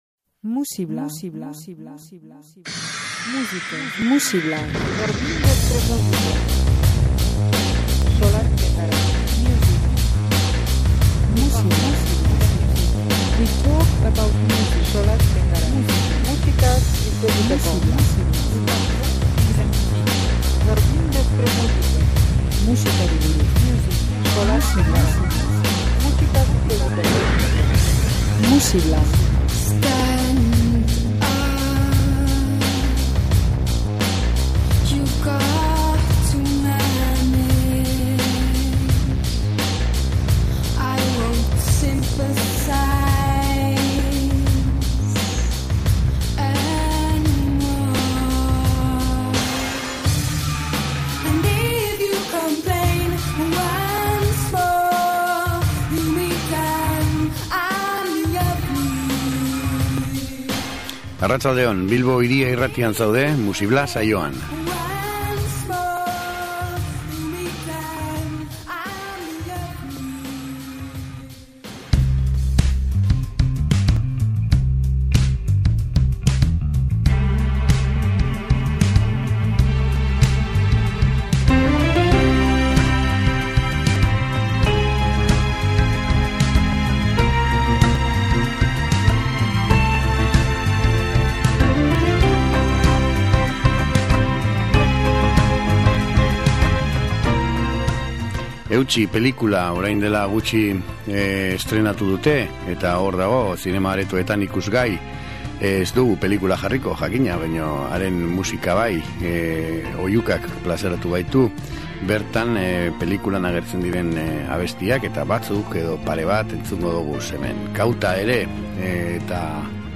Soinu gozoak.